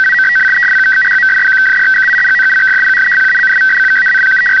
FEC-A /FEC-A Broadcast / FEC 100A / FEC 101
Likewise, even though station will be heard to return to idle mode at end of traffic, output will continue for a short period.
FEC-A 96 Baud, Shift 400 Hz, FC 1750 Hz   FEC-A 96 Baud, Shift 400 Hz, FC 1750 Hz